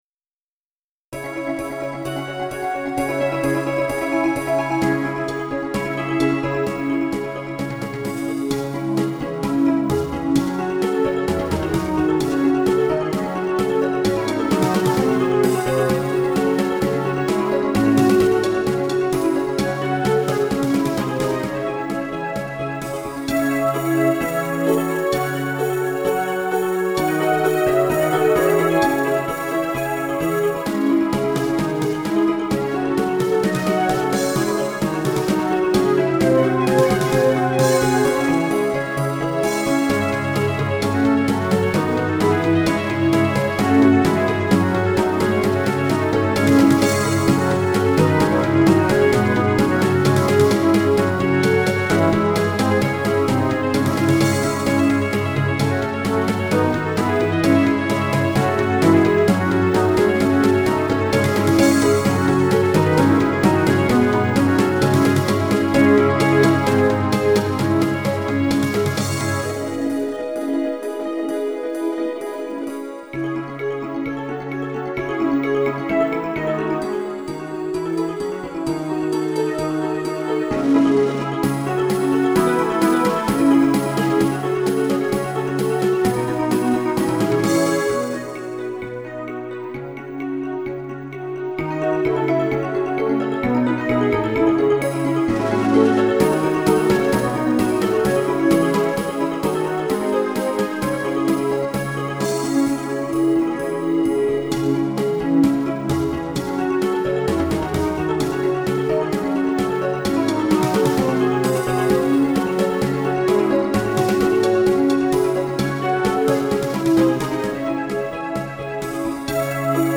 〜オフボーカル版〜